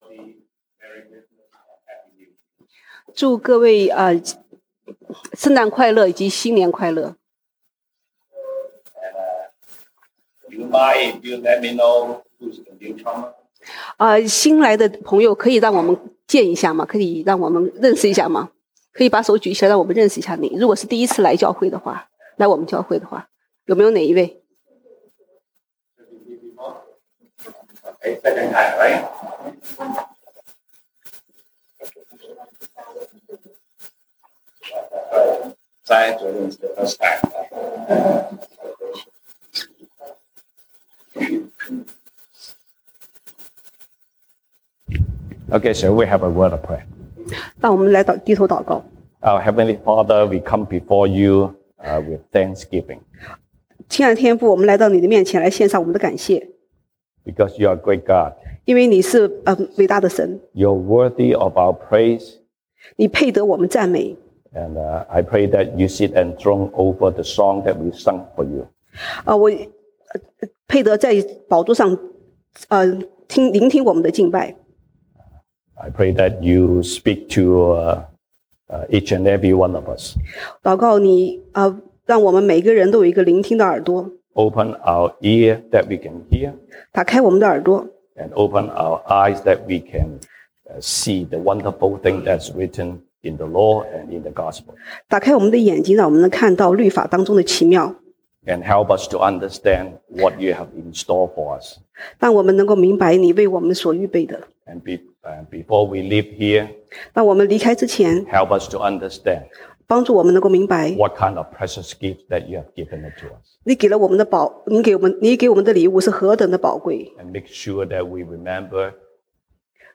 Passage: 以賽亞書 Isaiah 9:1-7 Service Type: 聖誕節聚會 Christmas Service Topics